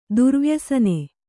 ♪ durvyasane